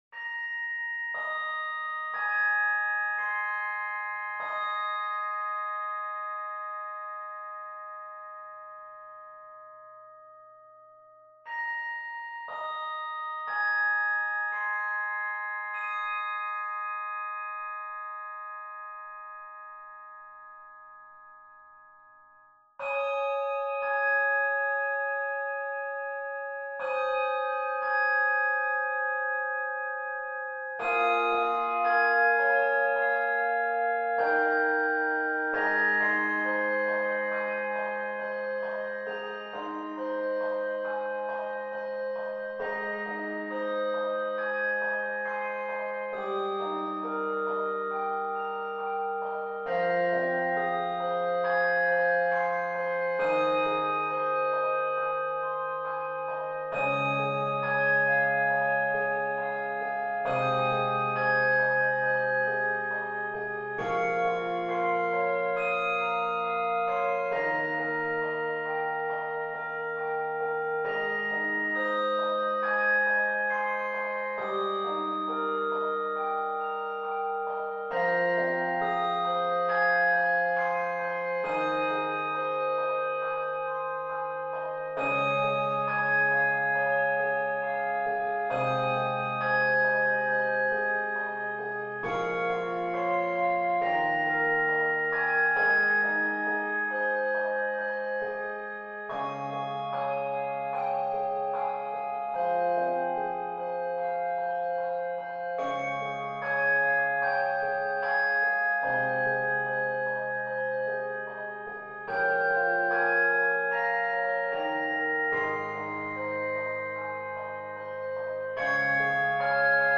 Key of Ab Major.